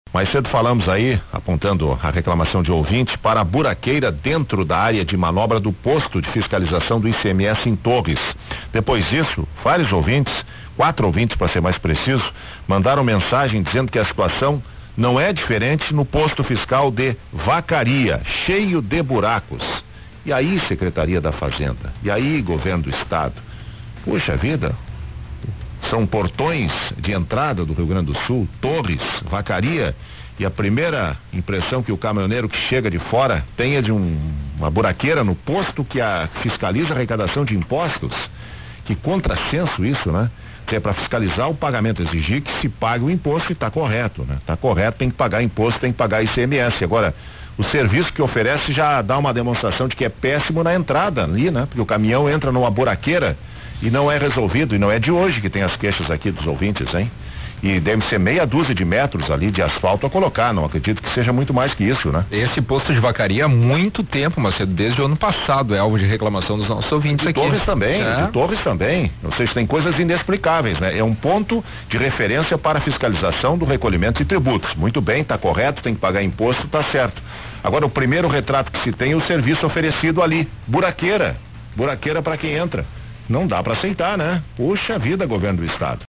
Ouvintes reclamam na Rádio Gaúcha de buraqueira nos Postos Fiscais de Vacaria e Torres